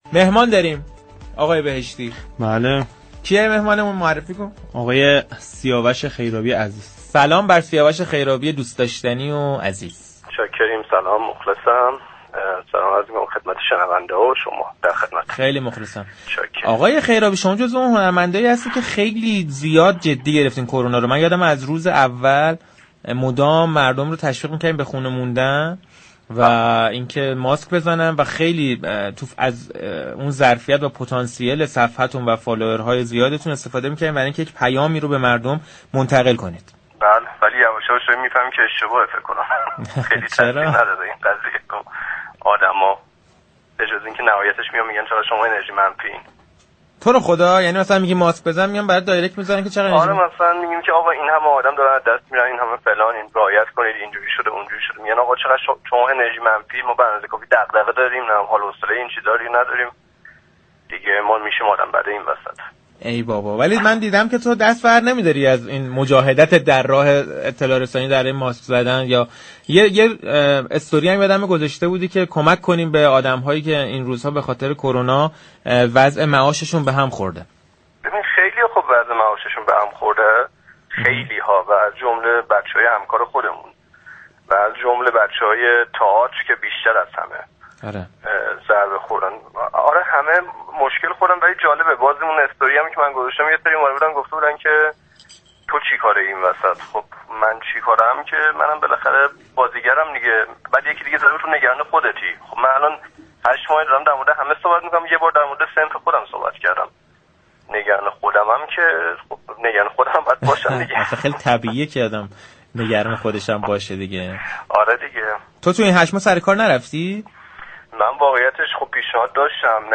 به گزارش پایگاه اطلاع رسانی رادیو تهران سیاوش خیرابی در گفتگو با برنامه پشت صحنه گفت: برخی مردم، من را به خاطر تاكید و توصیه بر استفاده از ماسك و رعایت فاصله گذاری اجتماعی مواخذه می كنند و معتقدند این كار من تنها انرژی منفی پراكنده می كند .